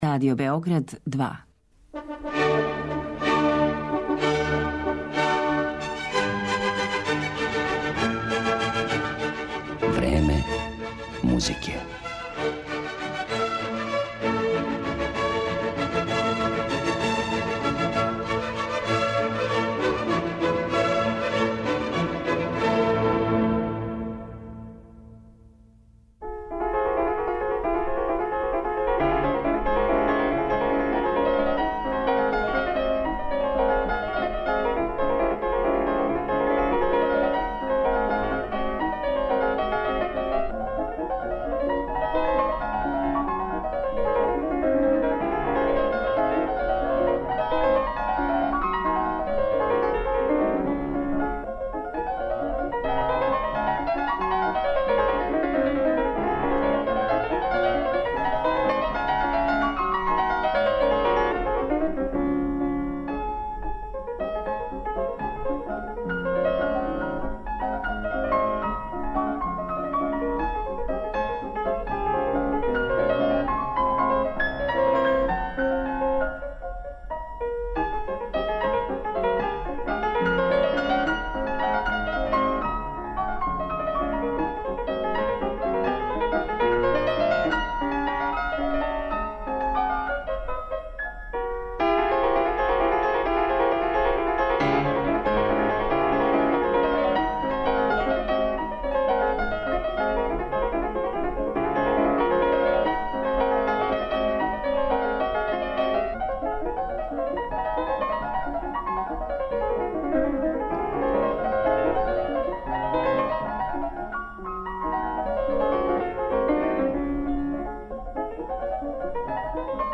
Представљамо пијанисту Чарлса Розена
У данашњем Времену музике , овог свестраног ствараоца представићемо кроз његове интерпретације дела Фредерика Шопена, Лудвига ван Бетовена, Франца Листа и Јохана Себастијана Баха.